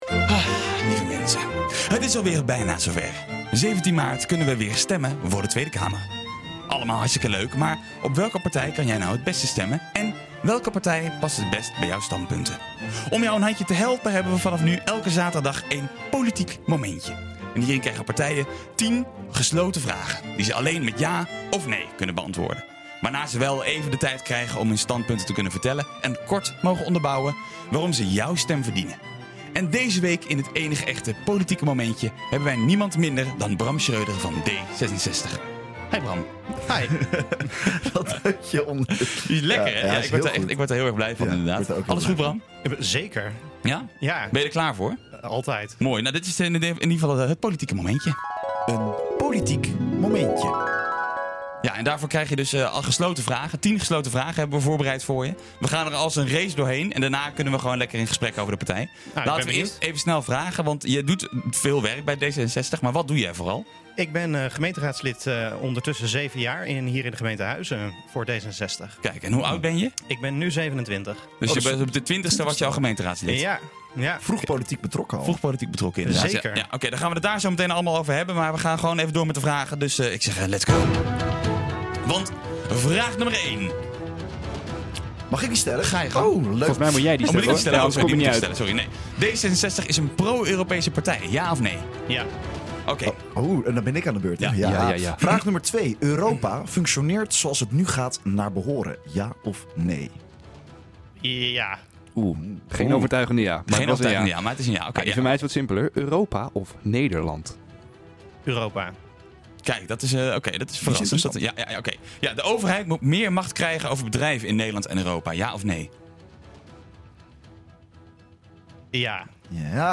Hierin krijgen politieke partijen 10 gesloten vragen, die ze alleen met ja of nee kunnen beantwoorden. Waarna ze wel even de tijd krijgen om hun standpunten te kunnen vertellen en kort mogen onderbouwen waarom ze jouw stem verdienen.